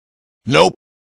دانلود آهنگ نوپ معروف میم ها از افکت صوتی انسان و موجودات زنده
دانلود صدای نوپ معروف میم ها از ساعد نیوز با لینک مستقیم و کیفیت بالا
جلوه های صوتی